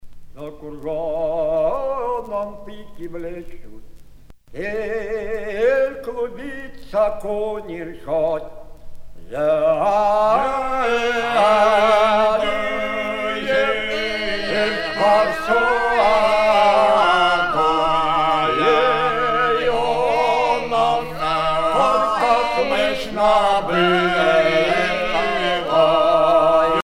Russian folksongs sung by the Don Cossacks
Pièce musicale éditée